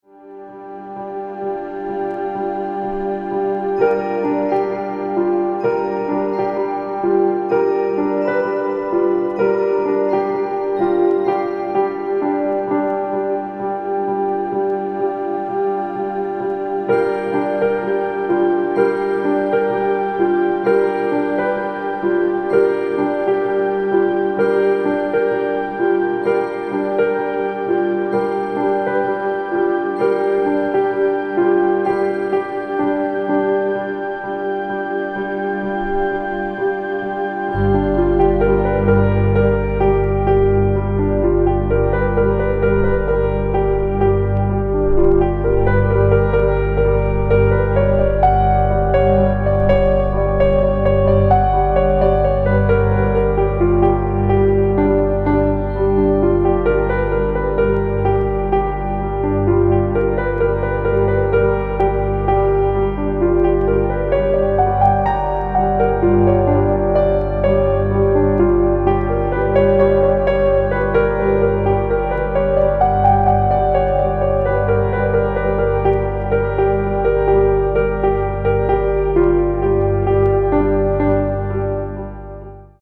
B4に小さなプレスミスがありますが、再生音への影響はほとんどありません。
ambient   electronic   experimental   synthesizer